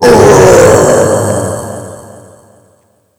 death_angry.wav